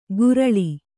♪ guraḷi